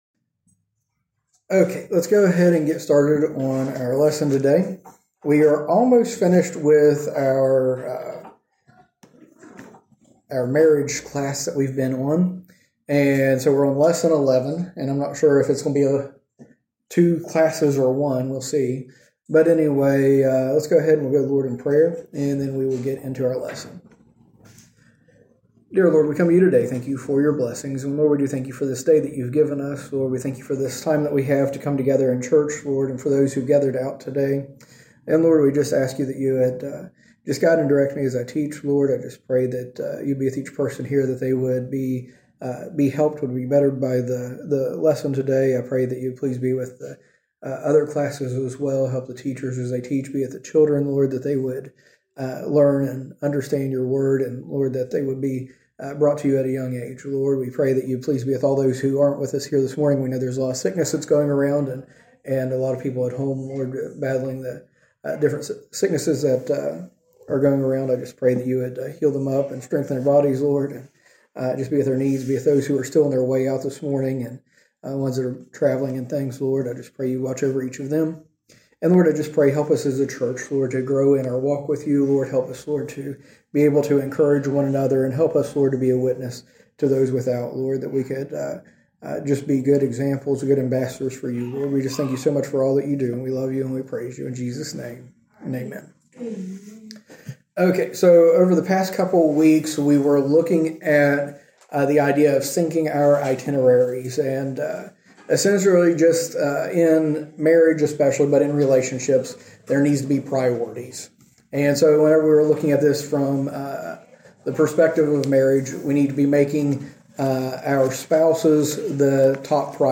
From Series: "Recent Sermons"